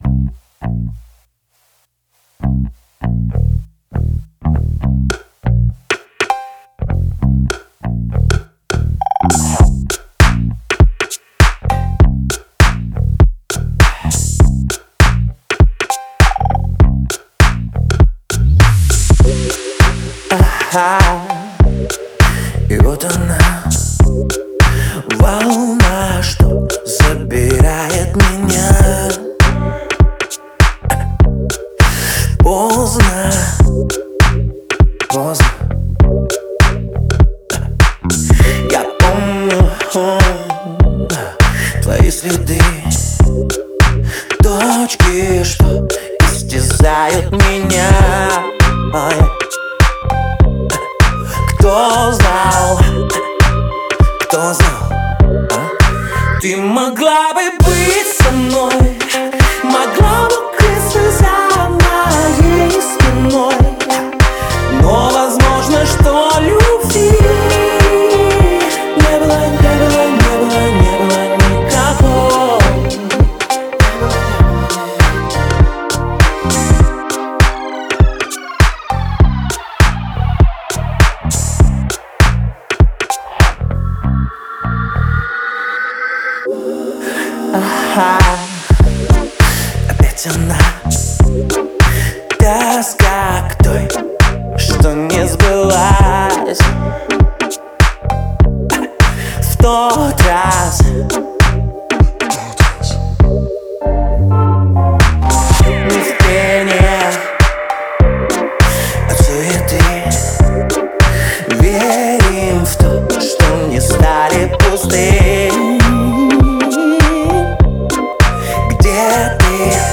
это проникновенная баллада в жанре поп